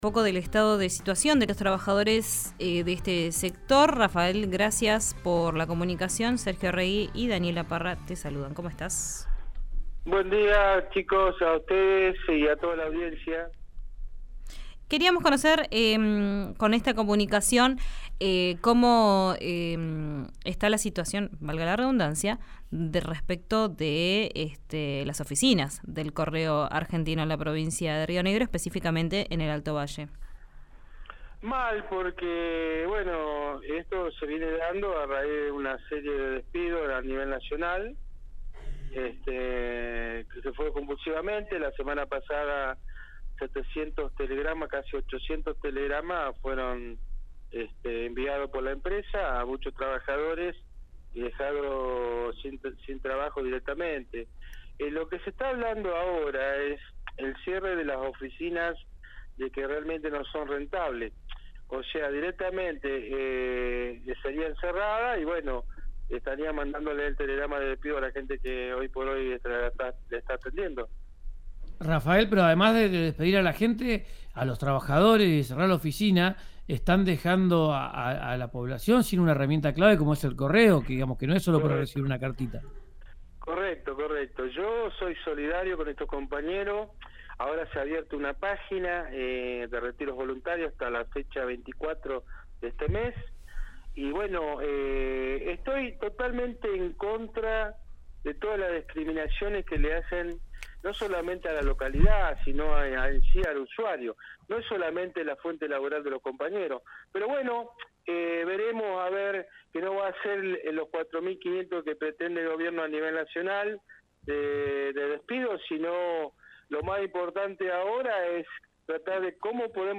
Casi 800 telegramas de despido fueron enviados por la empresa a muchos trabajadores», indicó el secretario en una entrevista realizada por RÍO NEGRO RADIO.